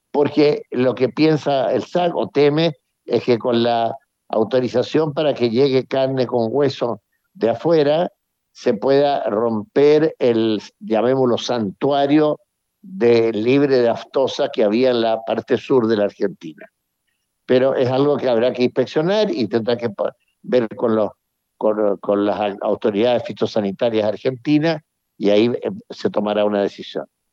En conversación con Radio Bío Bío, el embajador confirmó distintas gestiones que se han estado coordinando luego que se conociera la medida adoptada por el SAG de la suspensión de importación de carne y productos de origen animal desde la Patagonia, al sur del río Colorado.